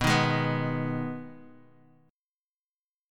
Abm/Cb Chord
Ab-Minor-Cb-x,2,1,1,0,x.m4a